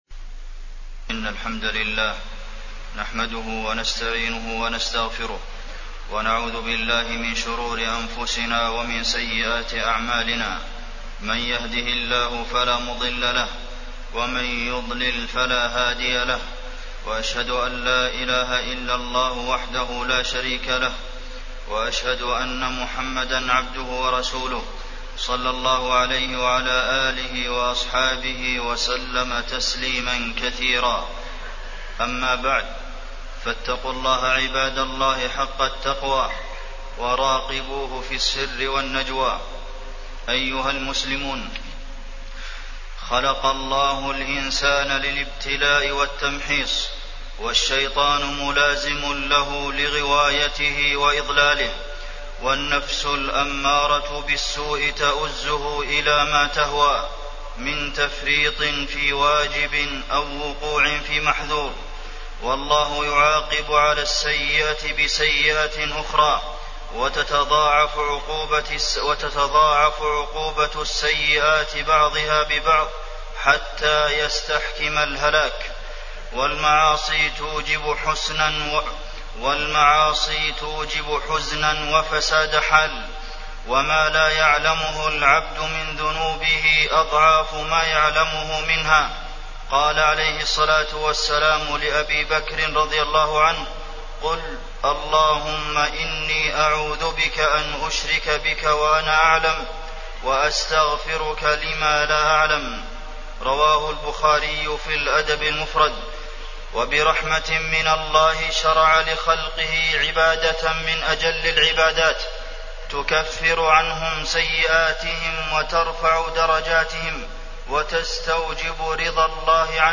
تاريخ النشر ٧ شعبان ١٤٢٩ هـ المكان: المسجد النبوي الشيخ: فضيلة الشيخ د. عبدالمحسن بن محمد القاسم فضيلة الشيخ د. عبدالمحسن بن محمد القاسم آثار الذنوب والمعاصي